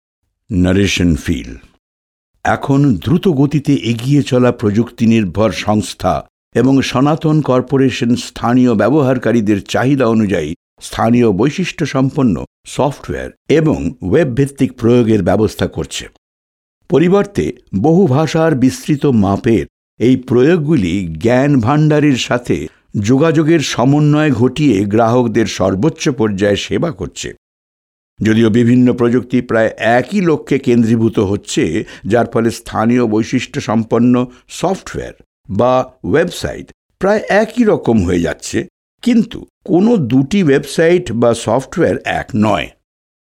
Bengali voiceover
Voiceover Artists EQHO provides multi-language solutions from its in-house recording facilities Language list 01 NEW Bengali Male 03995 NARRATION